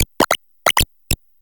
Cri de Famignol Famille de Trois dans Pokémon HOME.